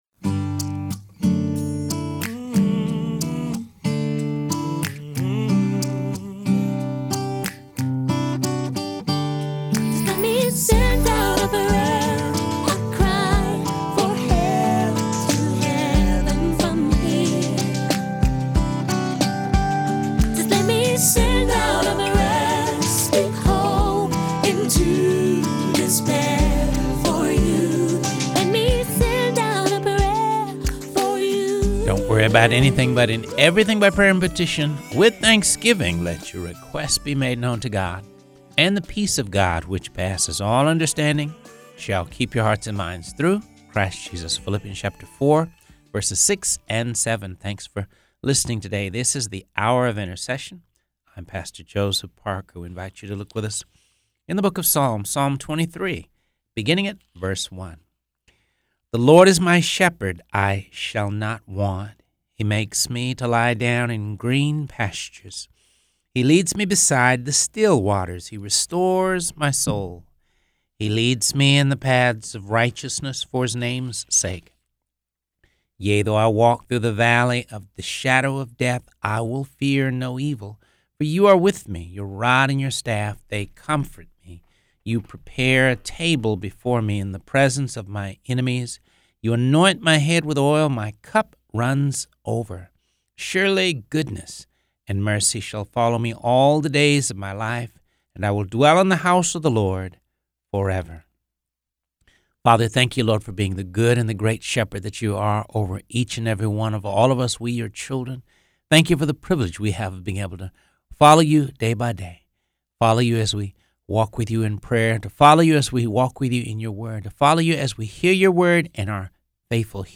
read through the Bible.